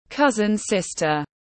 Chị em họ tiếng anh gọi là cousin sister, phiên âm tiếng anh đọc là /ˈkʌz.ənˌsɪs.tər/.
Cousin sister /ˈkʌz.ənˌsɪs.tər/